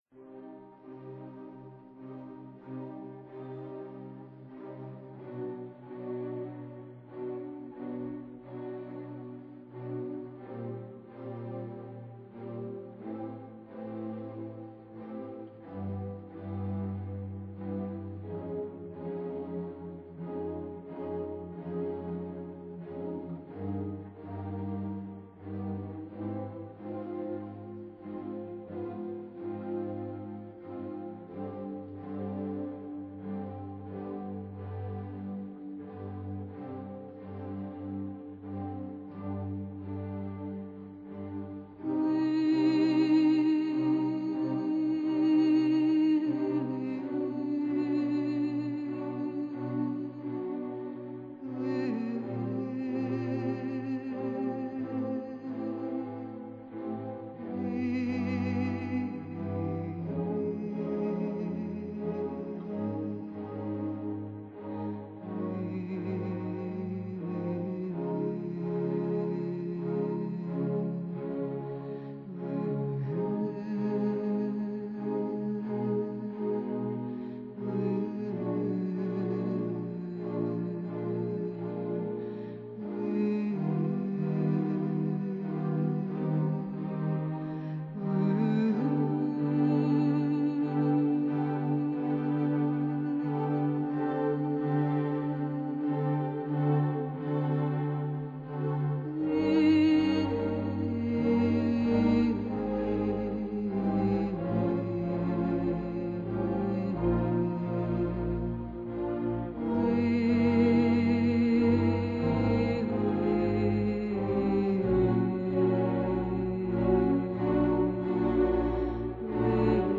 类似于非洲土著雄壮呐喊的女声与纯朴民族乐风的结合是如此的魅力无边！